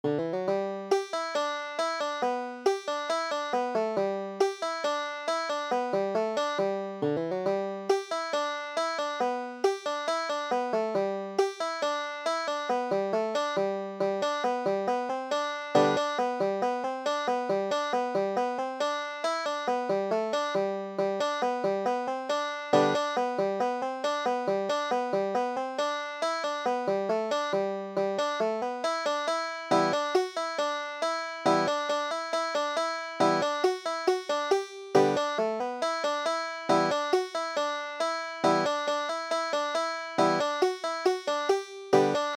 Banjo
Stoney Point Standard G G   tab | audio tab |